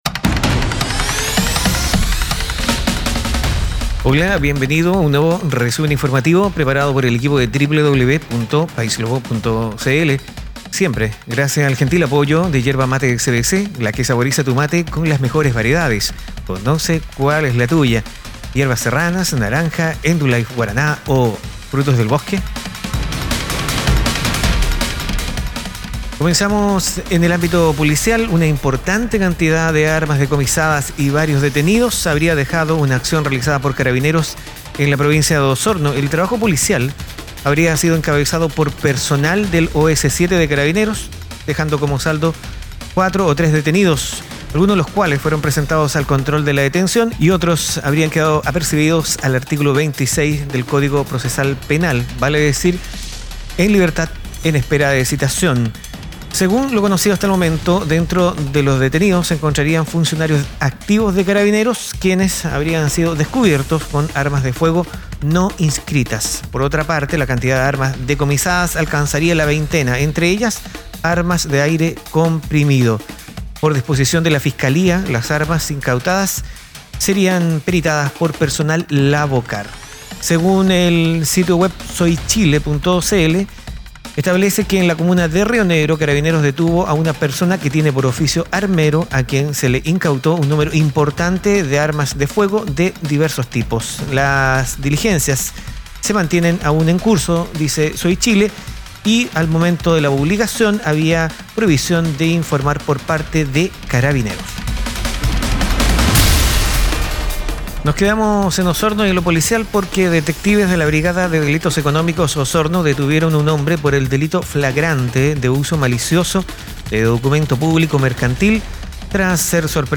RESUMEN INFORMATIVO 13JUN19